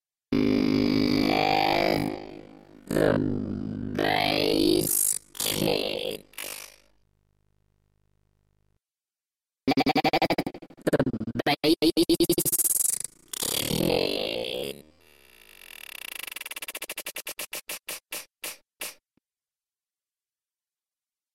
This tutorial will make use of some interesting wiring techniques in Reason to create a vocal time stretching effect.